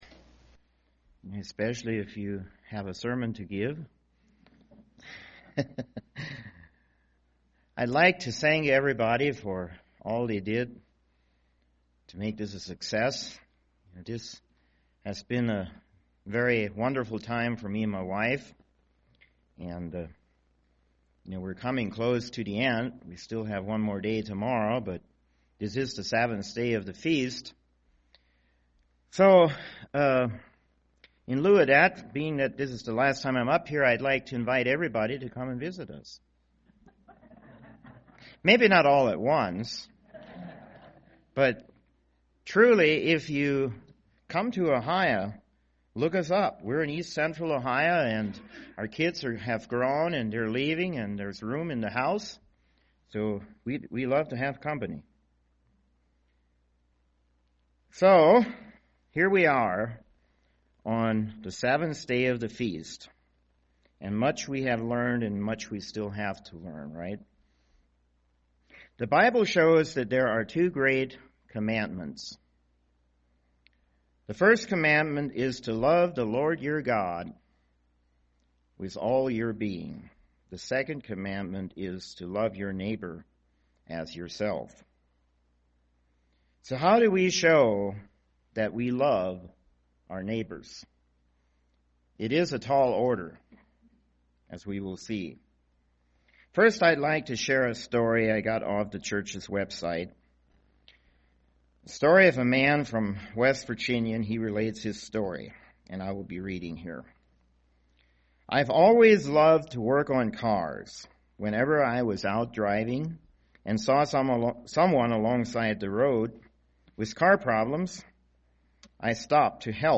This sermon was given at the Bigfork, Montana 2014 Feast site.